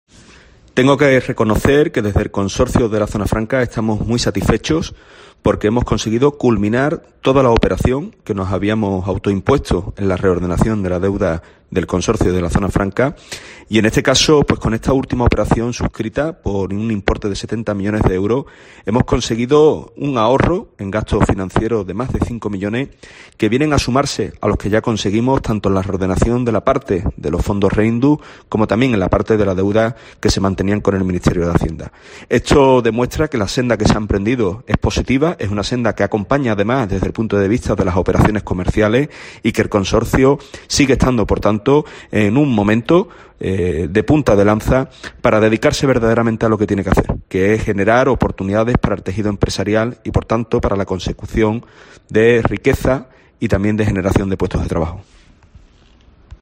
Fran González, Delegado del Estado en la Zona Franca de Cádiz, habla de la refinanciación de la deuda del Consorcio